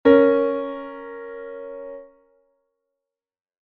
harmonico.mp3